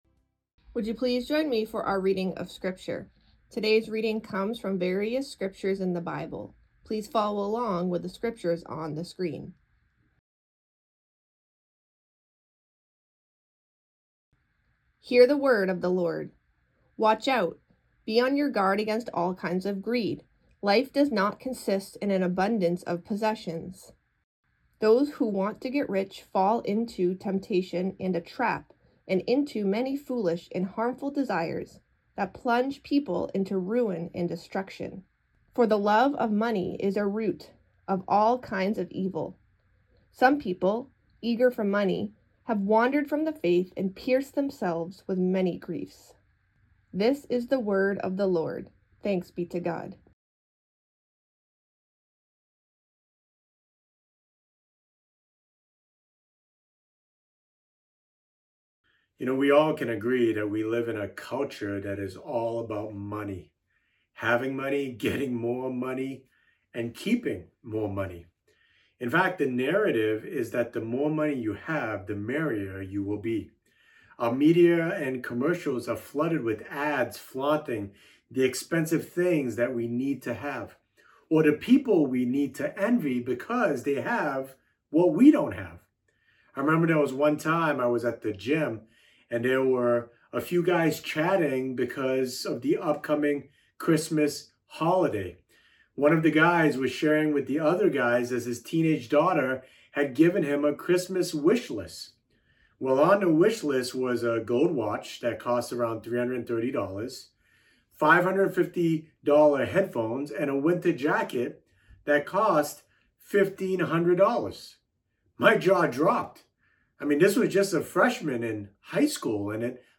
Part 1: Heart Evaluation - Sermons - Community City Church